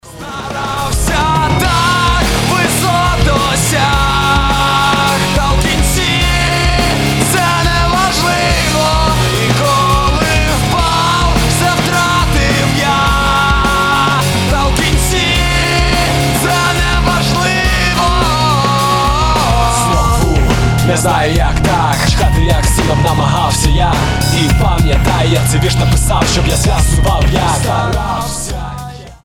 • Качество: 320, Stereo
громкие
Cover
nu metal
Рэп-рок